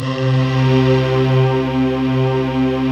Index of /90_sSampleCDs/Optical Media International - Sonic Images Library/SI1_Breath Choir/SI1_Soft Breath